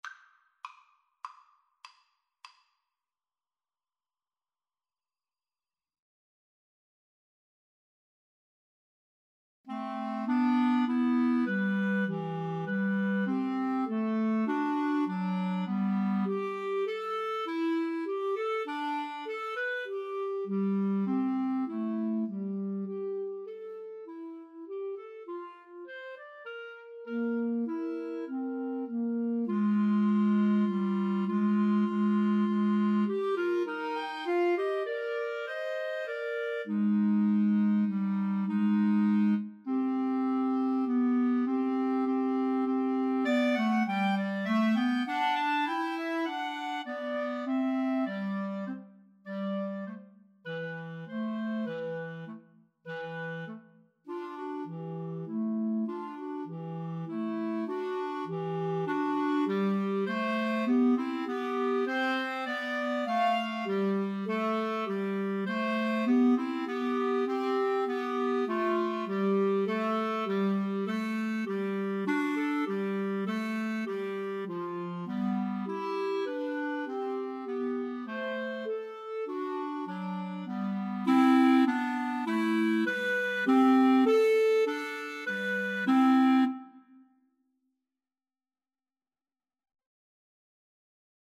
C major (Sounding Pitch) D major (Clarinet in Bb) (View more C major Music for Clarinet Trio )
5/4 (View more 5/4 Music)
Allegro guisto (View more music marked Allegro)
Clarinet Trio  (View more Easy Clarinet Trio Music)
Classical (View more Classical Clarinet Trio Music)